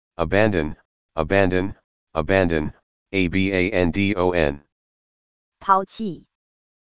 合併後是這樣(美國男聲)+(台灣女聲)